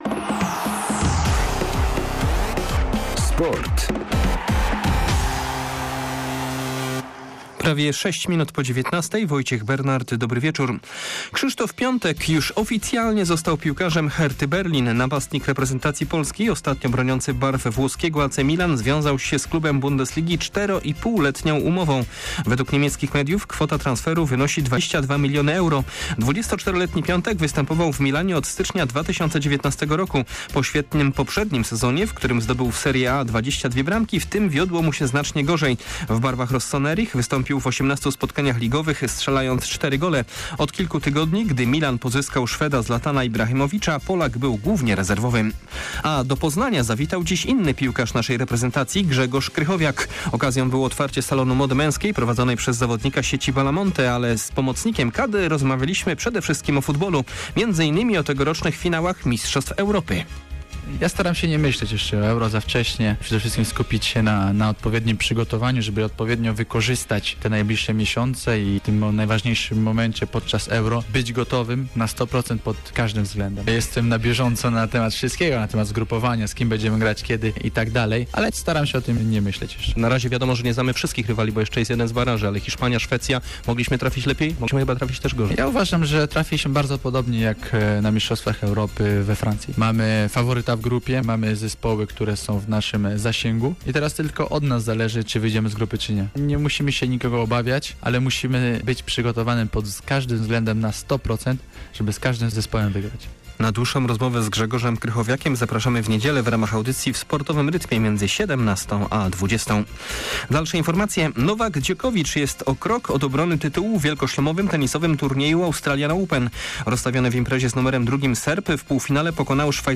30.01. SERWIS SPORTOWY GODZ. 19:05